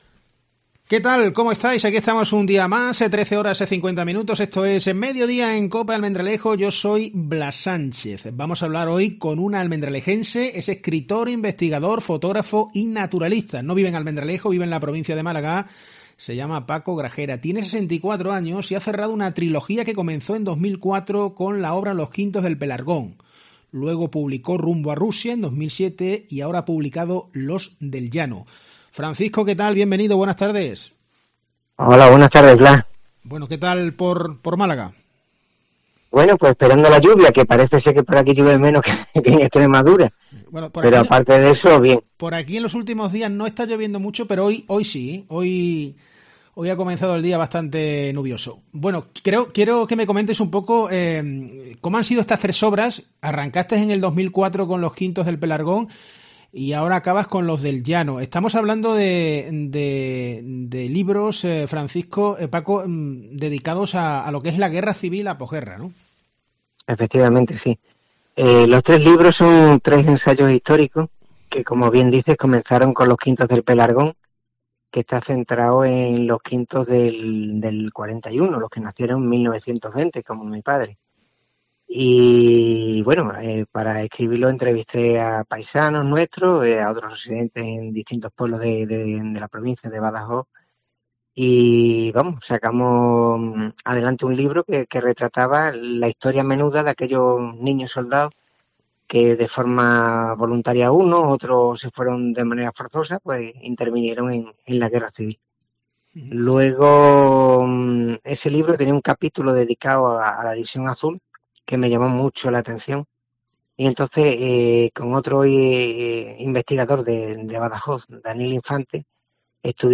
En COPE, lo hemos entrevistado.